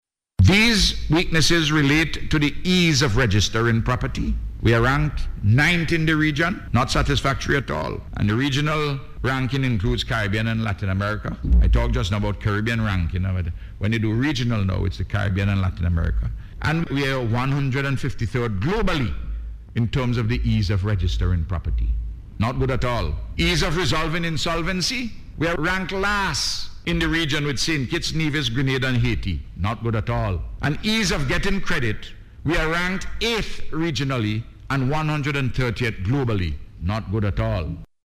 Delivering his 2015 Budget Address in Parliament, Prime Minister Gonsalves said three main areas of weakness affected this country’s overall ranking.